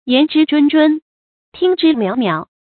注音：ㄧㄢˊ ㄓㄧ ㄓㄨㄣ ㄓㄨㄣ ，ㄊㄧㄥ ㄓㄧ ㄇㄧㄠˇ ㄇㄧㄠˇ